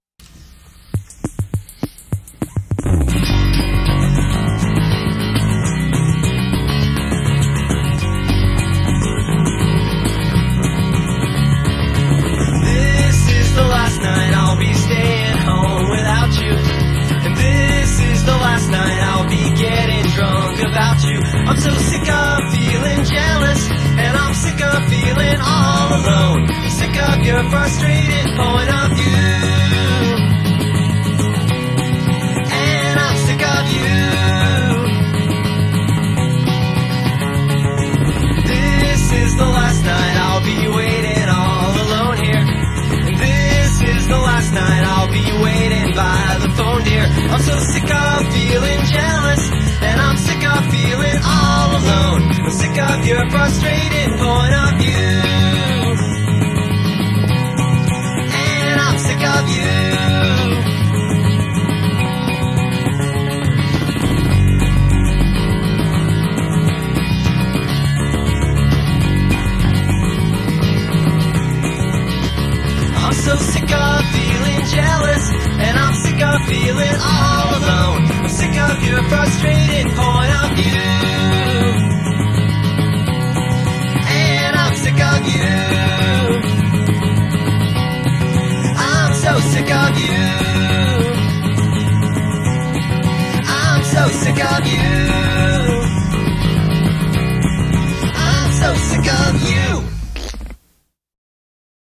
On this occasion, the mighty Casio MT-40 had been pressed into service as my personal Grant Hart.